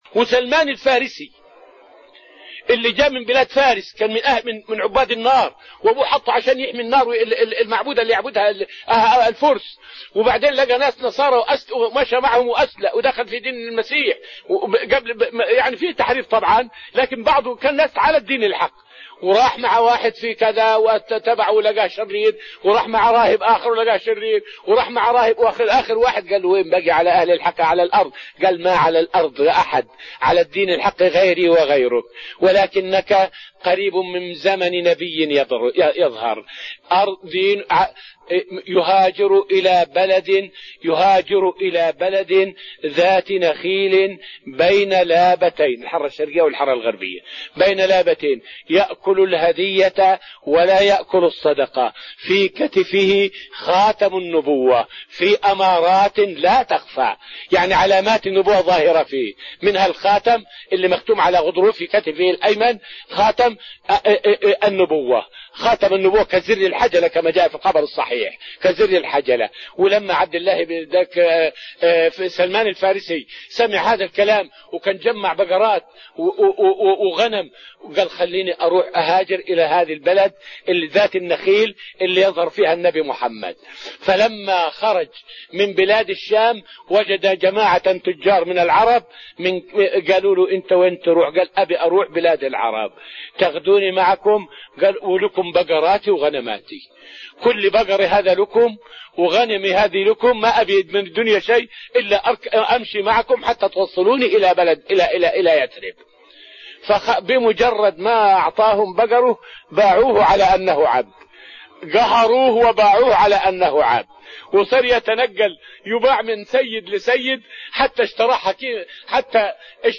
فائدة من الدرس السادس من دروس تفسير سورة الأنفال والتي ألقيت في رحاب المسجد النبوي حول إسلام سلمان الفارسي رضي الله عنه.